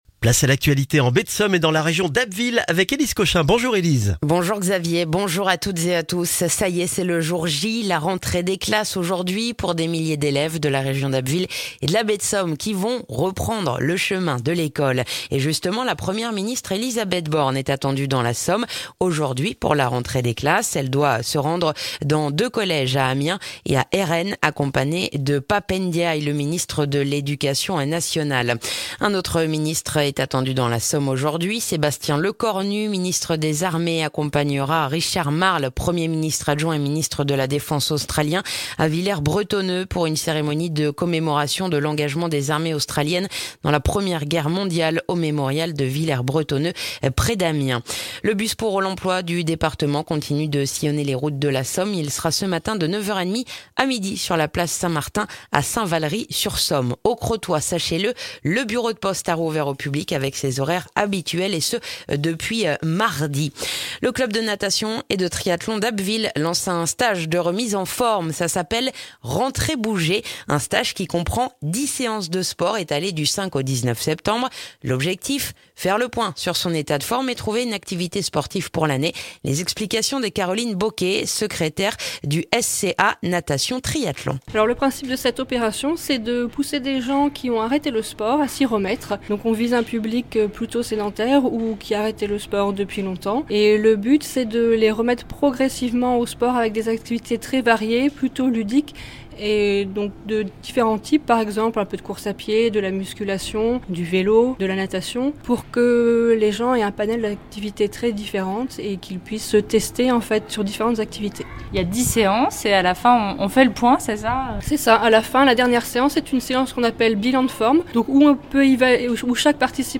Le journal du jeudi 1er septembre en Baie de Somme et dans la région d'Abbeville